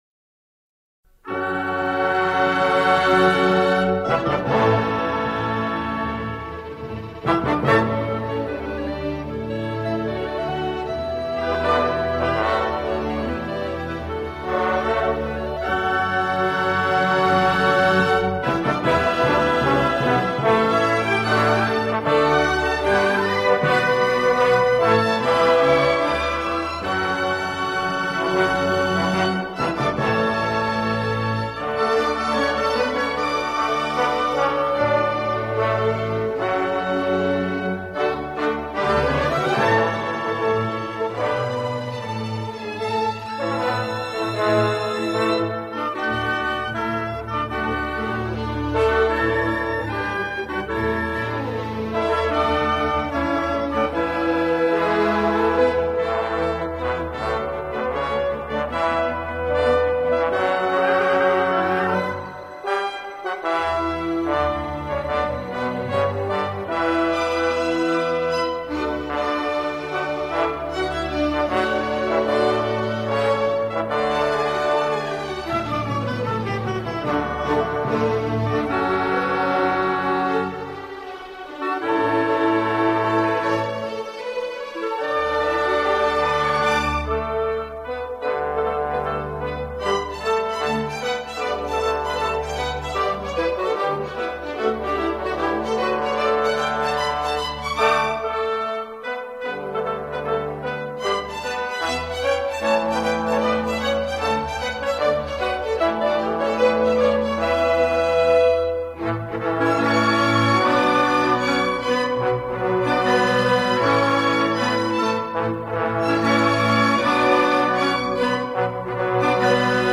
بی‌کلام